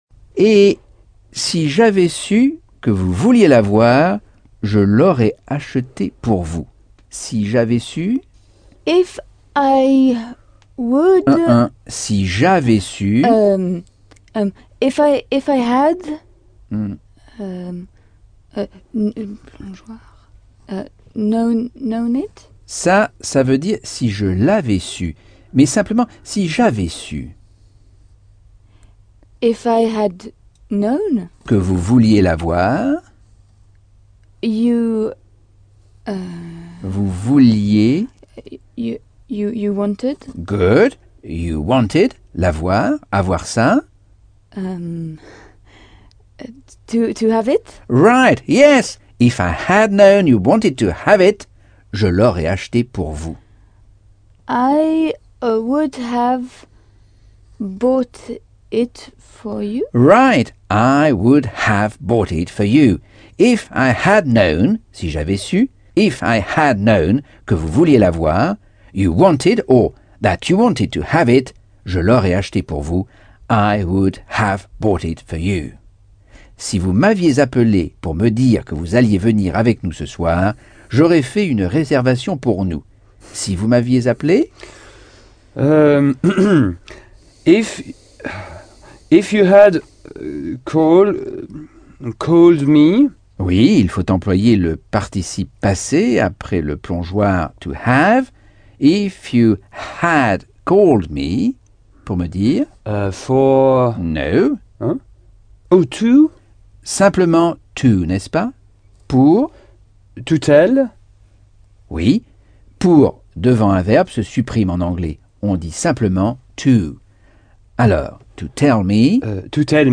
Leçon 5 - Cours audio Anglais par Michel Thomas - Chapitre 9